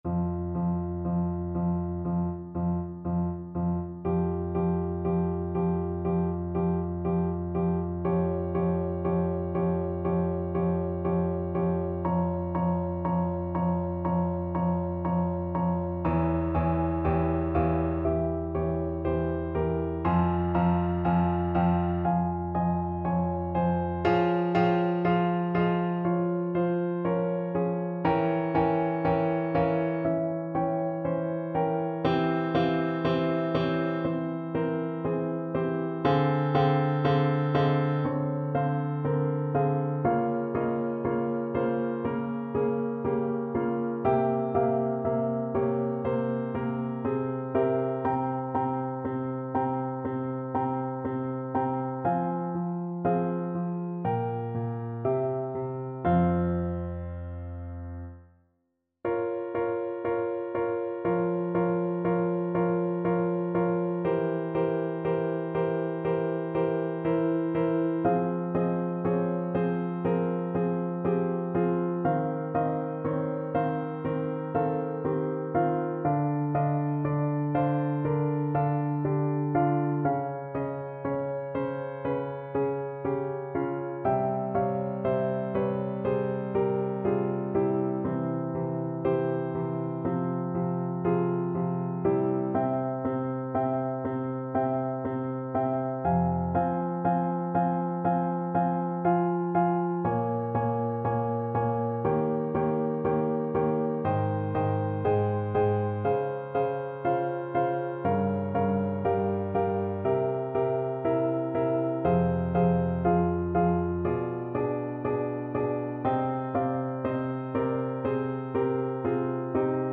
Classical Voice
Piano Playalong MP3